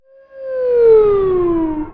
sci-fi_alarm_siren_object_pass_01.wav